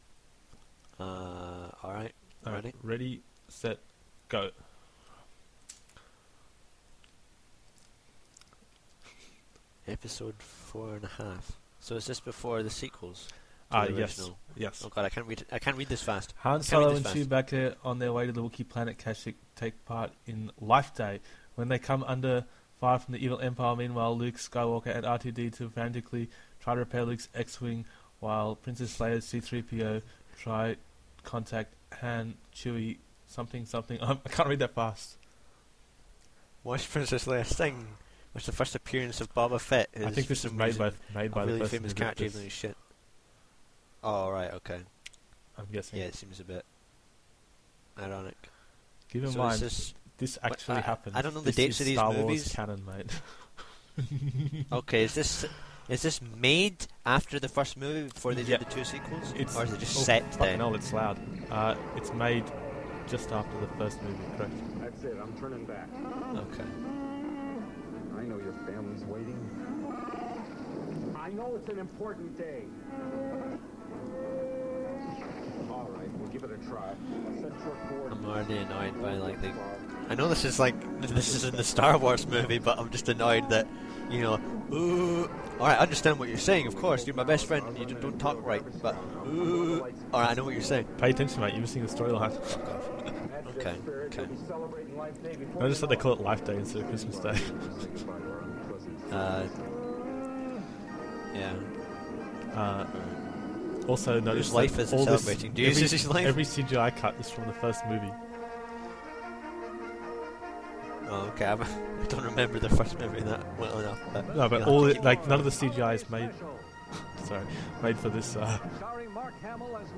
The Star Wars Holiday Special Commentary